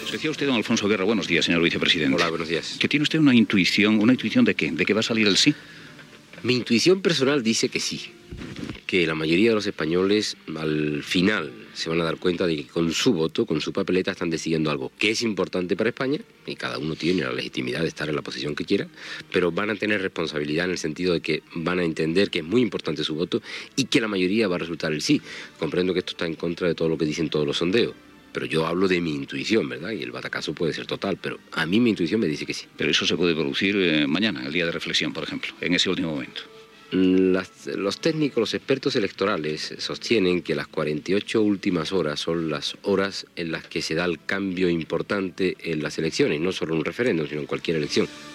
Fragment d'una entrevista al vicepresident del govern espanyol, Alfonso Guerra, arran del referèndum de l'OTAN i el seu possible resultat
Info-entreteniment